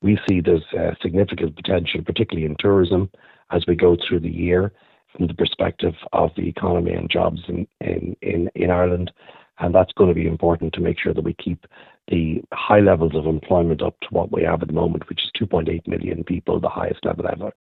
Brian Hayes, Chief Executive of the BPFI, says the tourism sector could help to keep employment high………